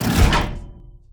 gun-turret-deactivate-02.ogg